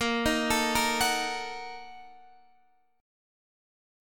A#M7sus4#5 chord